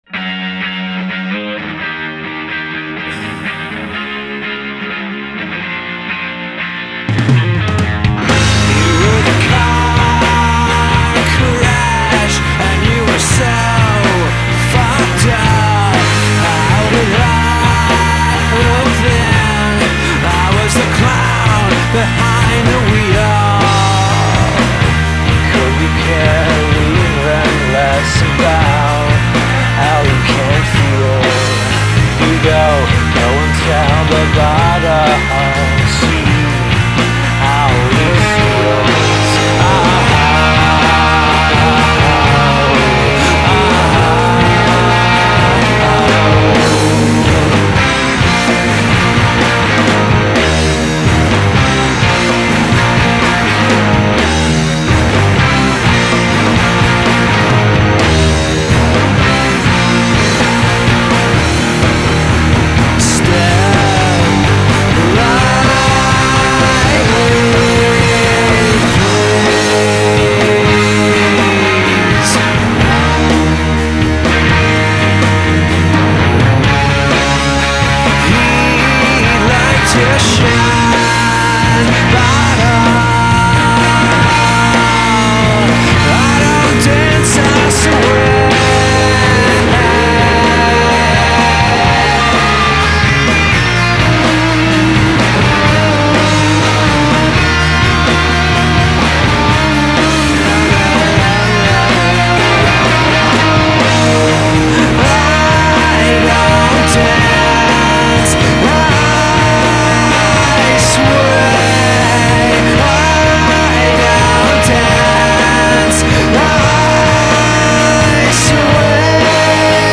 vocals
guitar
bass
drums